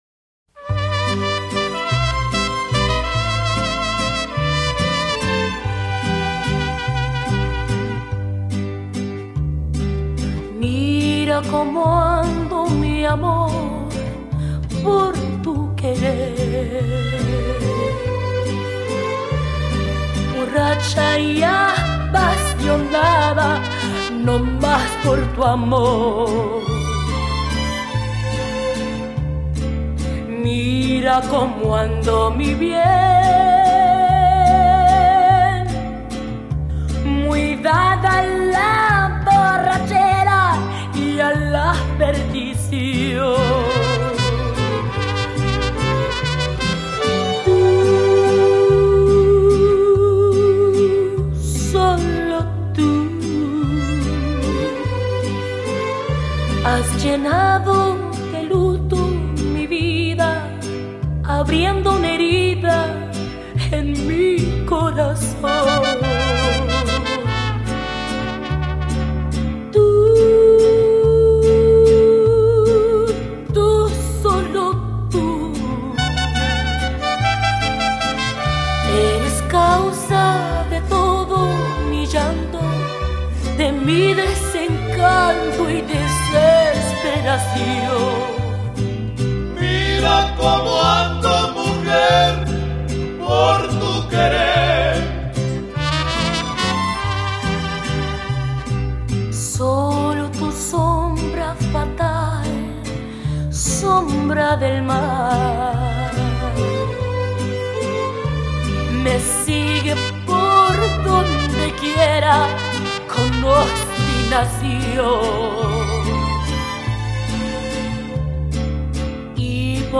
texmex